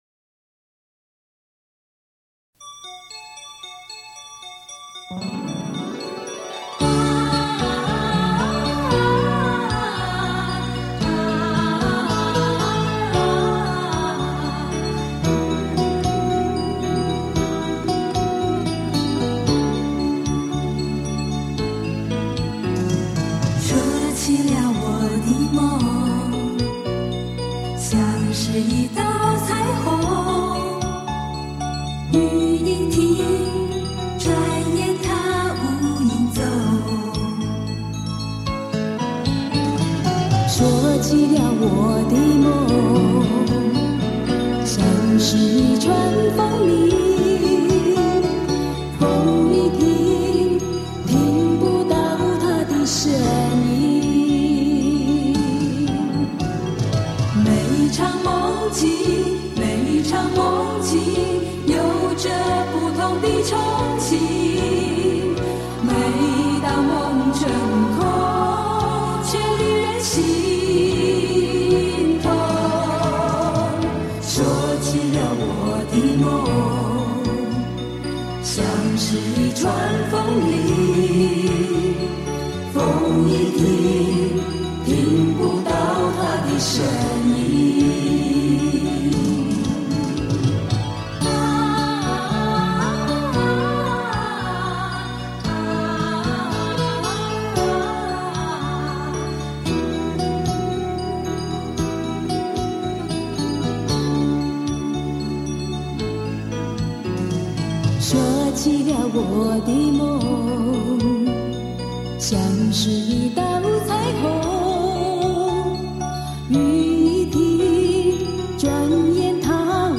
倔强而温柔的声音
纯真而甜美的声音
深沉而清洌的声音
婉转悠扬的声音
荡气回肠的声音